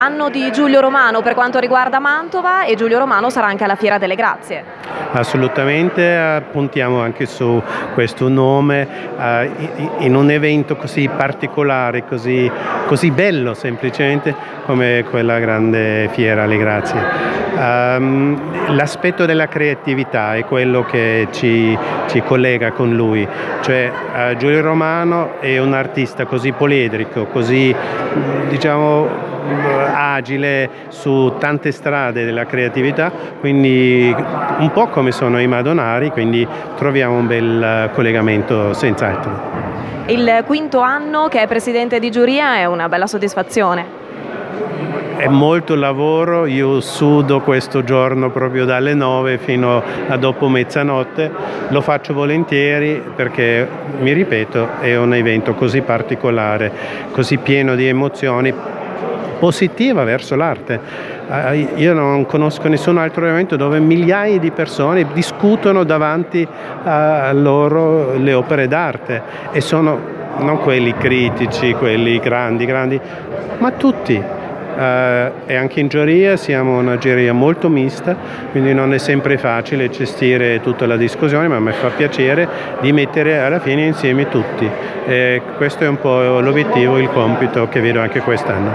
Di questo importante sodalizio ce ne ha parlato Peter Assmann, direttore di Palazzo Ducale: